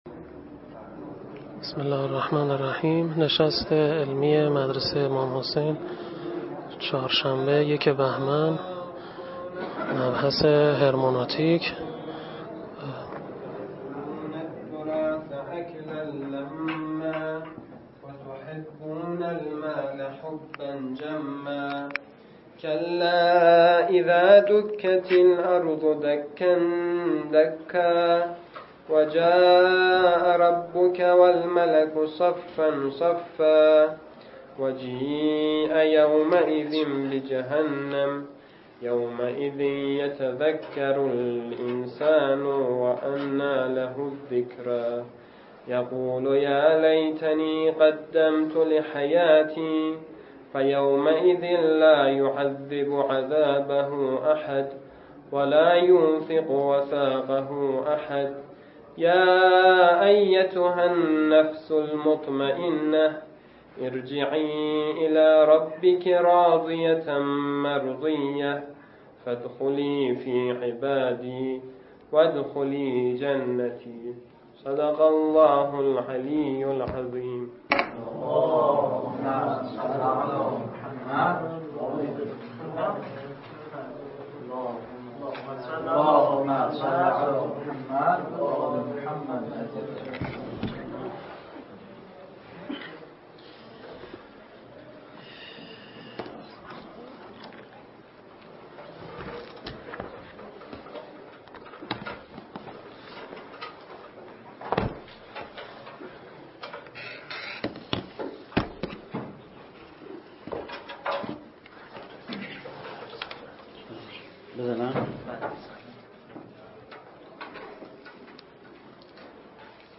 نشست علمی
همچنین این نشست علمی به صورت پخش زنده از طریق کانال ایتای مدرسه امام حسین (علیه السلام) ارائه شد.